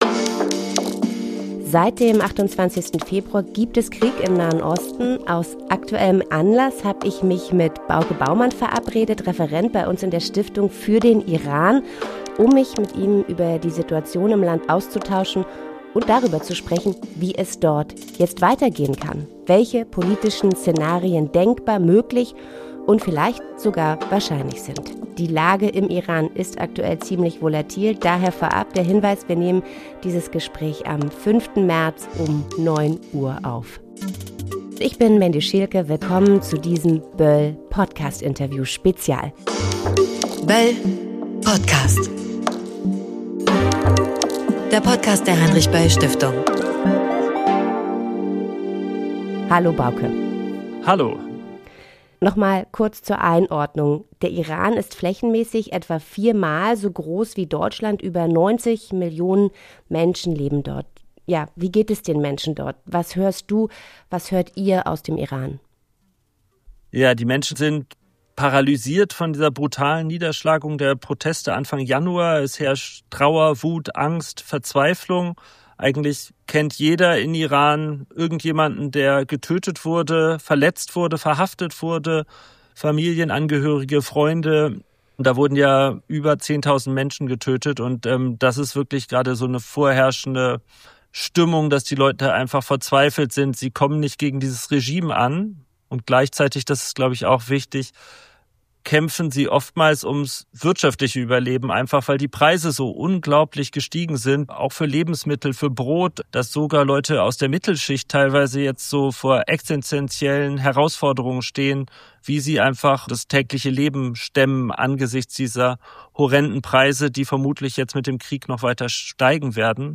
Interviewspezial: Krieg in Nahost – Wie geht es weiter im Iran? ~ böll.podcast Podcast